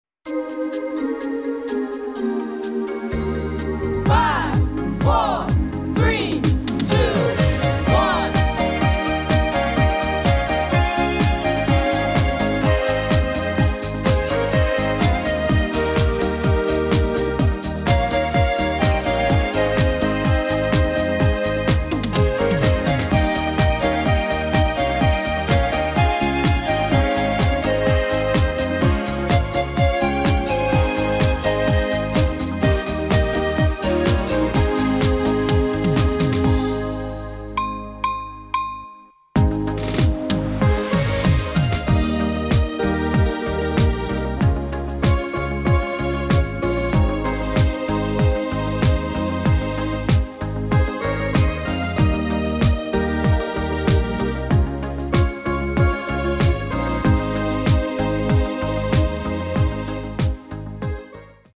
30 Sekunden aktiver Teil. 20 Sekunden Pause